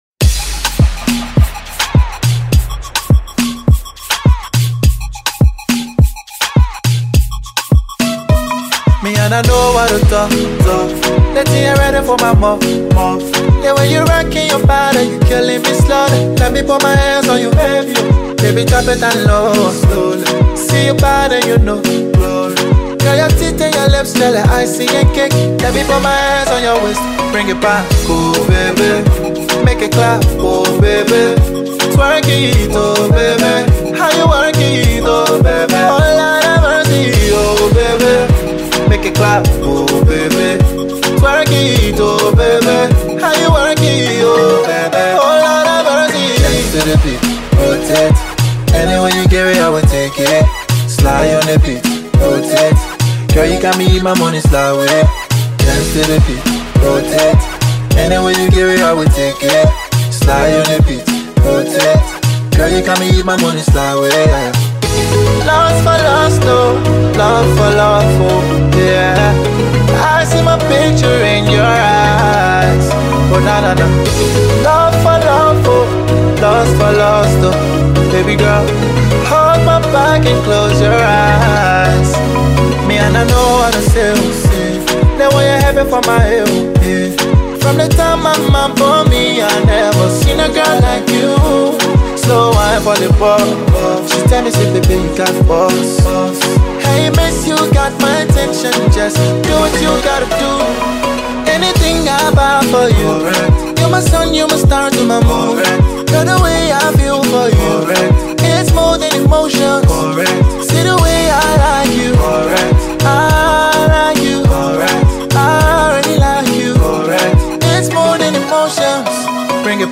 / RnB / By